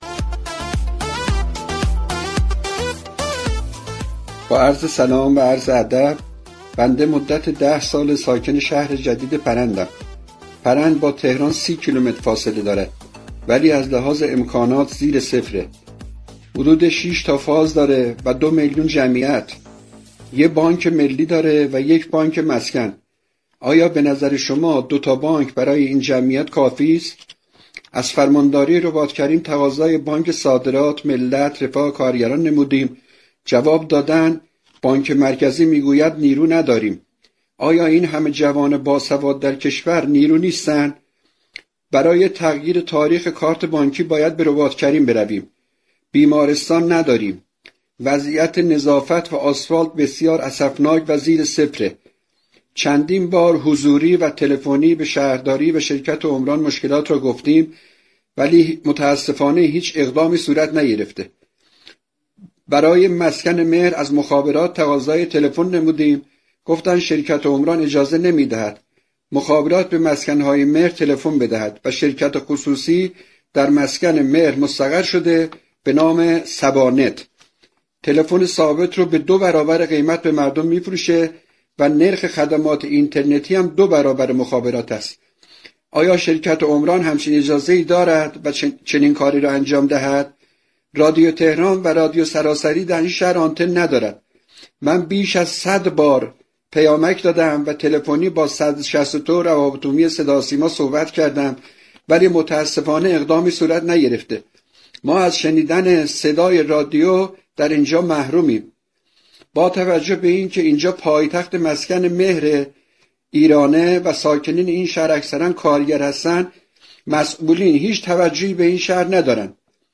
عبدالرضا چراغعلی، معاون هماهنگی امور عمرانی استانداری تهران در گفت‌وگوی برنامه سعادت آباد كه از شبكه رادیویی تهران پخش شد؛ اعلام كرد: 12 هزار واحد مسكونی در شهر پرند 85 درصد پیشرفت فیزیكی داشته‌اند و 15 درصد باقی مانده در مرحله نازك كاری است و تا 4 ماه آینده به بهره برداری كامل می‌رسد.